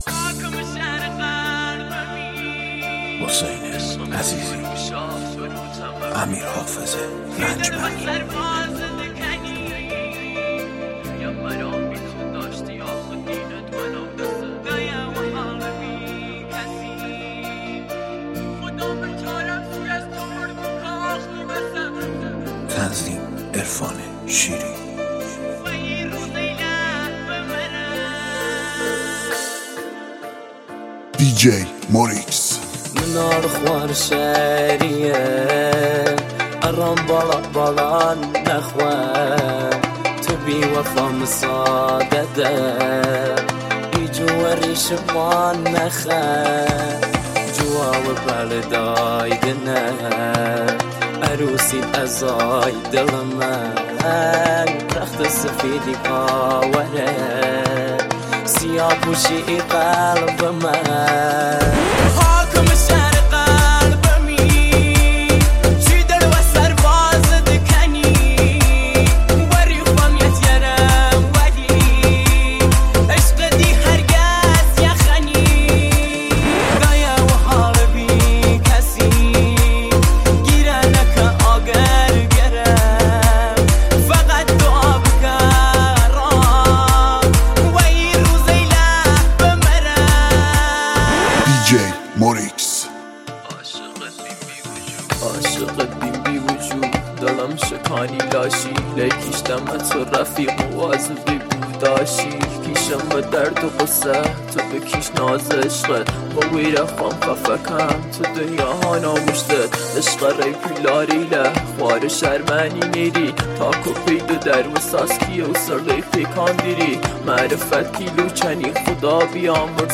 اهنگ کردی
ریمیکس